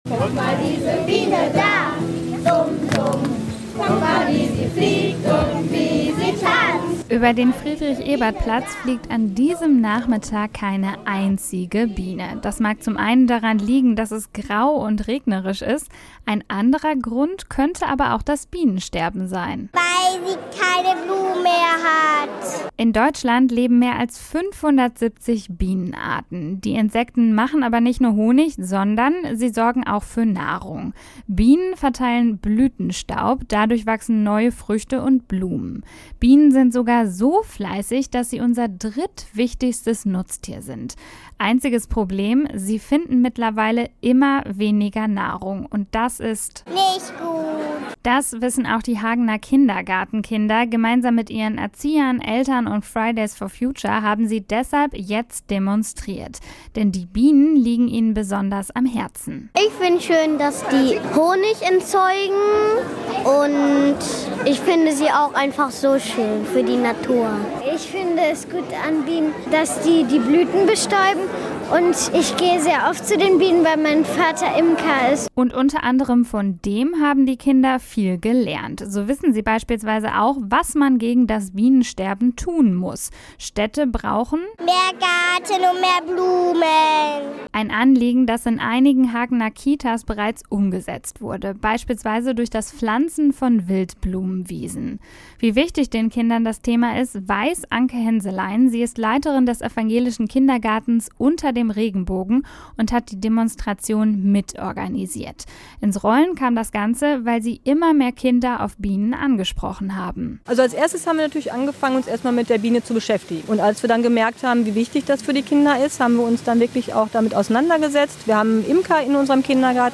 Demonstration gegen das Bienensterben